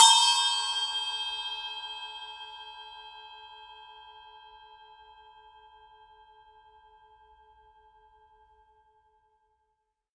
8" Cup Chime
8_cup_chime_edge.mp3